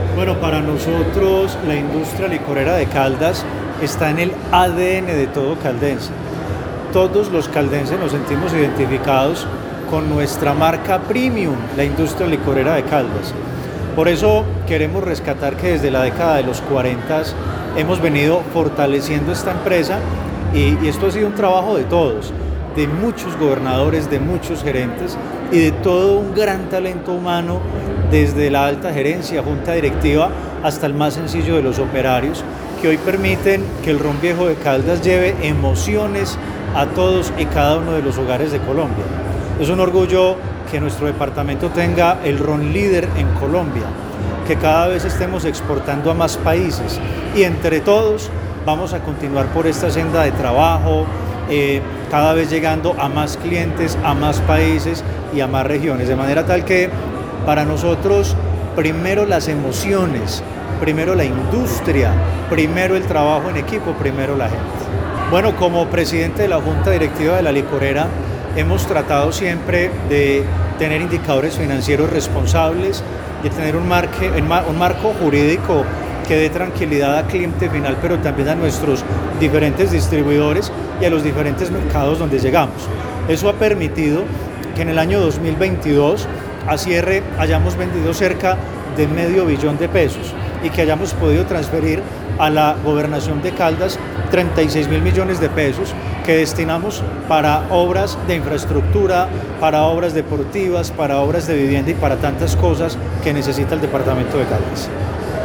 El pasado jueves se llevó a cabo en la Gobernación de Caldas un acto de conmemoración de las bodas de roble (80 años) de la empresa más importante del departamento.
Luis Carlos Velásquez Cardona, Gobernador de Caldas, afirmó en su intervención que la Industria Licorera de Caldas está en el ADN de todos los caldenses.
Audio Luis Carlos Velásquez Cardona, Gobernador de Caldas
Luis-Carlos-Velasquez-Gobernador-de-Caldas.mp3